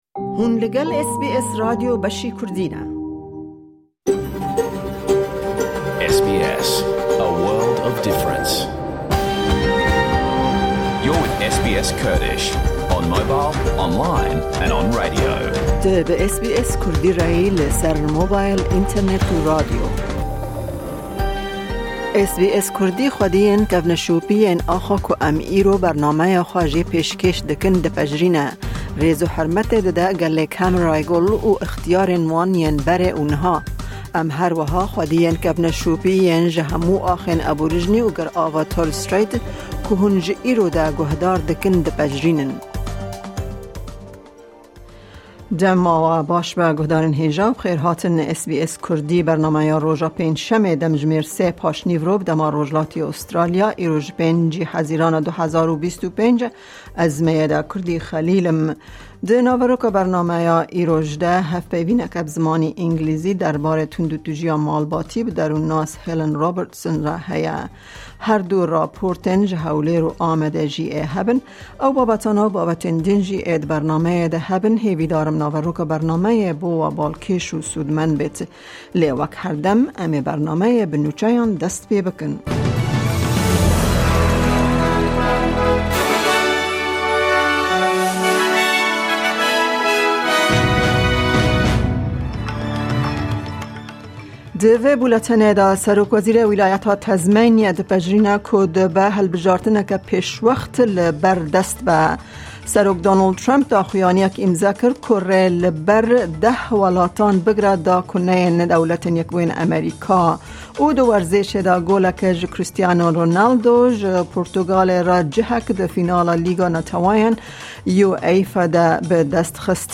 Nûçe, hevpeyvîn, raporên ji Amed û Hewlêre û babetên cur bi cur hene.